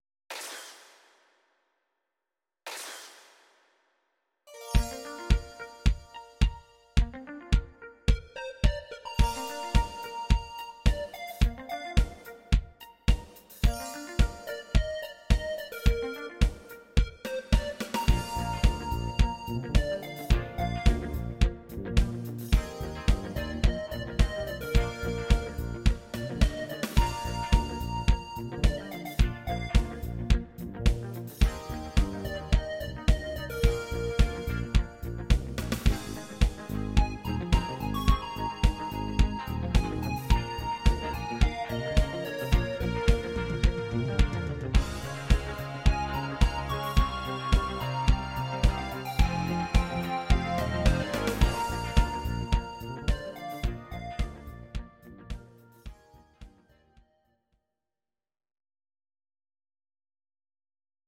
These are MP3 versions of our MIDI file catalogue.
Your-Mix: Disco (724)